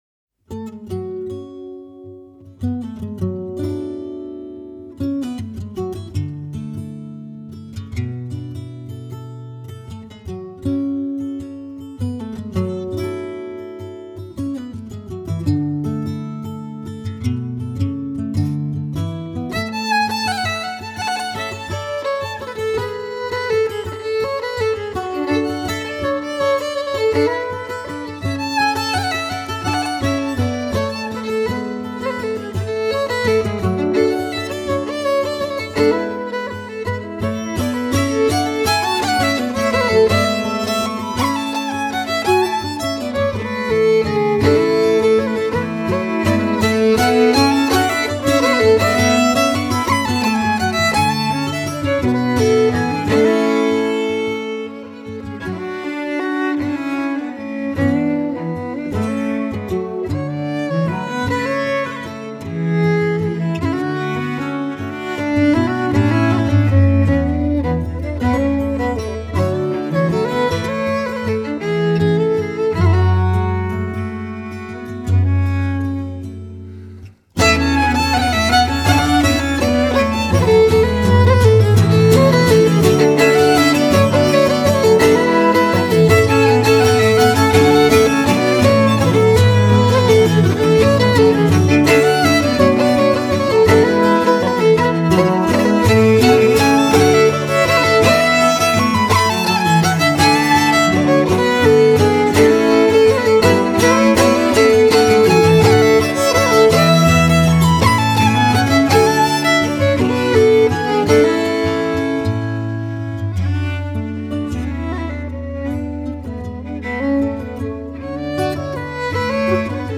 Fiddle
Guitar
Cello